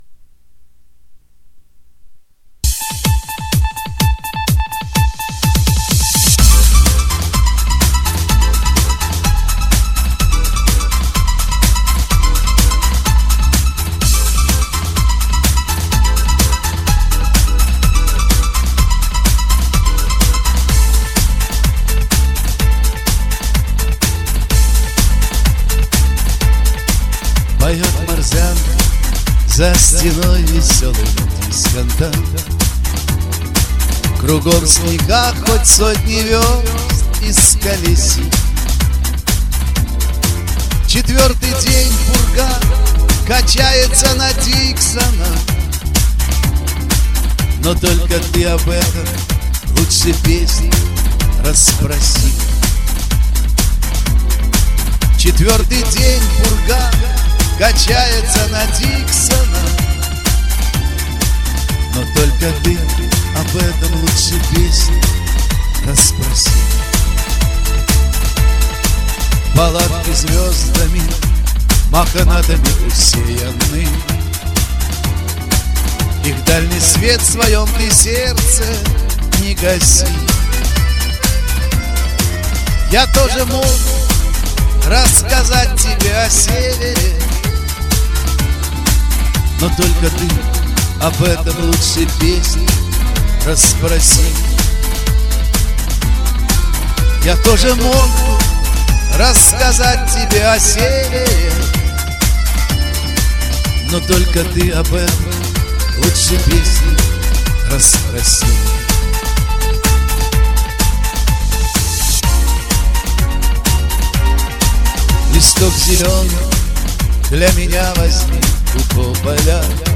"     римейк исполн.